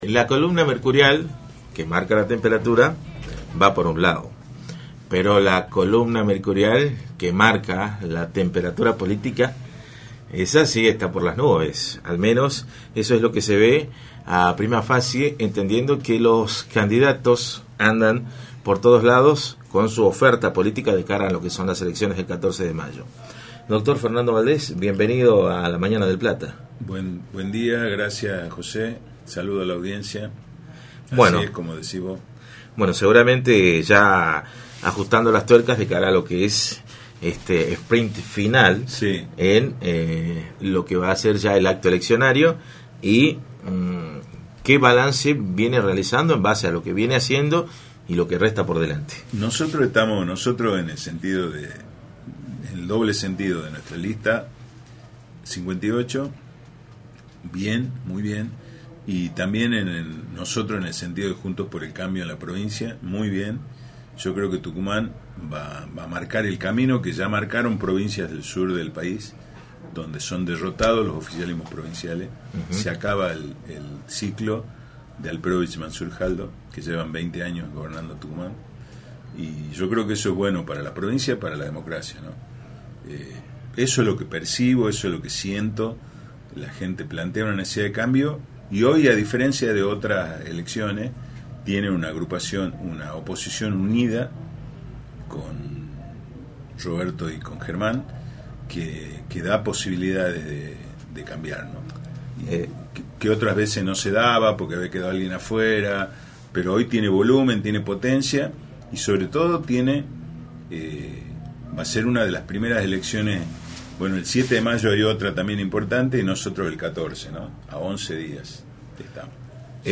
visitó los estudios de Radio del Plata Tucumán, por la 93.9
entrevista